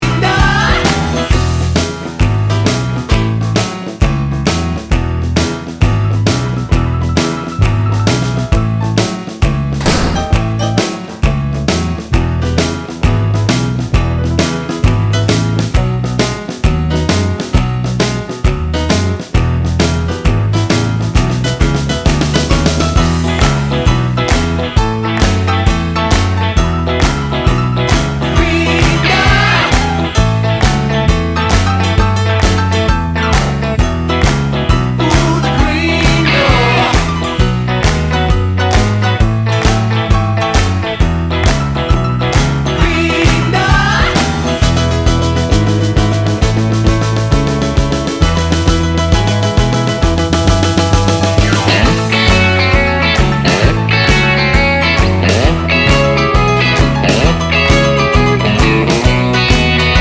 End Cut Down Pop (1980s) 2:45 Buy £1.50